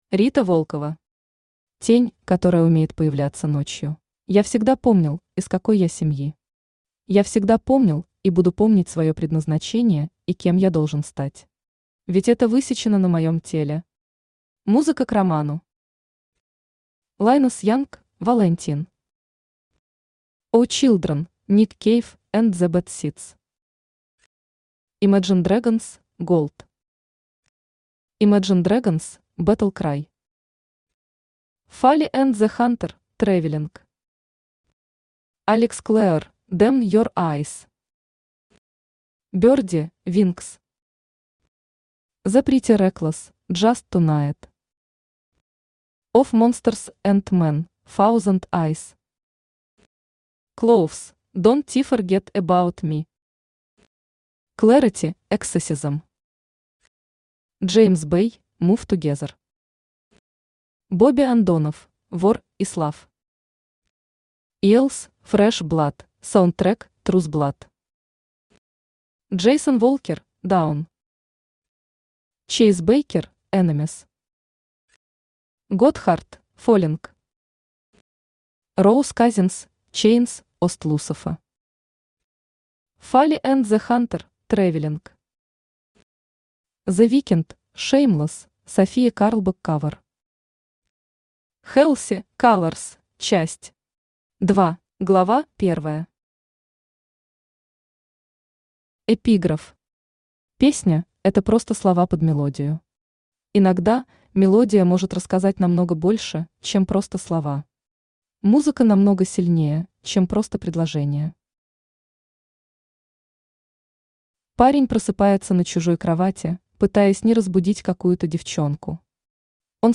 Аудиокнига Тень, которая умеет появляться ночью | Библиотека аудиокниг
Aудиокнига Тень, которая умеет появляться ночью Автор Рита Волкова Читает аудиокнигу Авточтец ЛитРес.